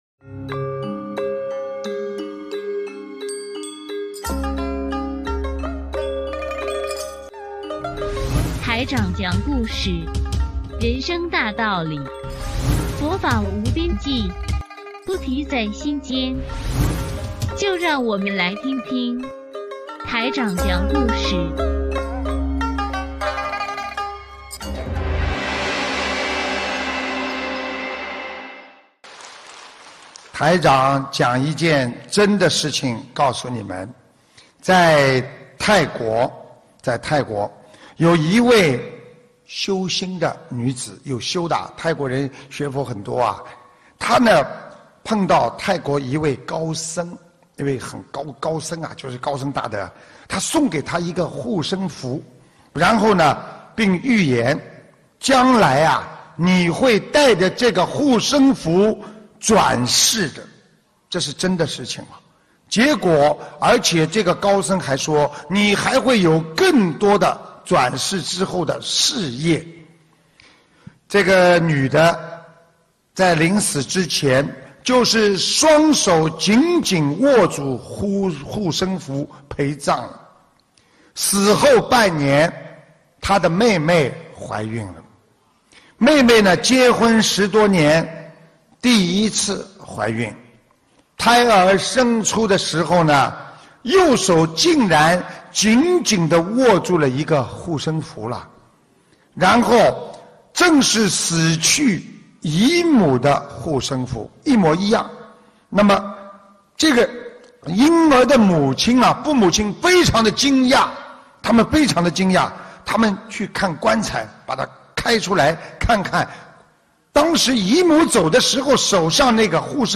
音频：投胎转世·师父讲小故事大道理